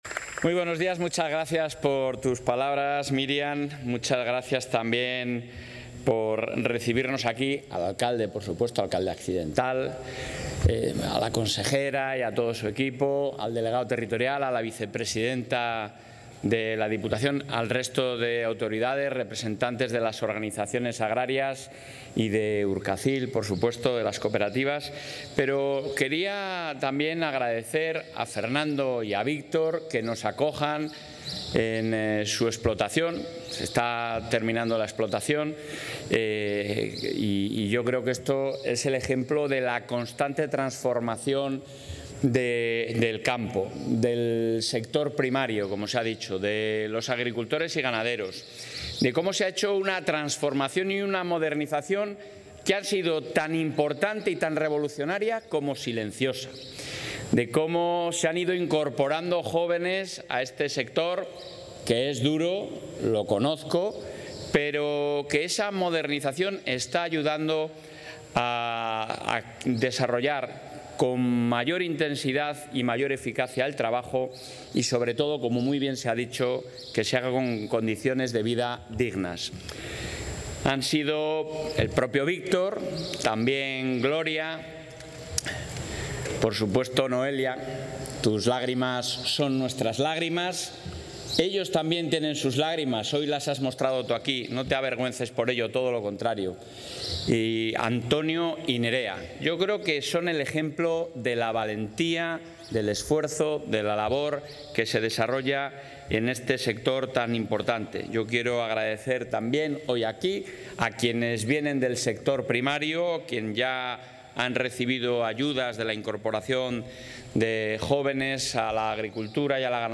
El presidente de la Junta de Castilla y León, Alfonso Fernández Mañueco, ha presentado hoy en la localidad palentina de Quintana...
Intervención del presidente de la Junta.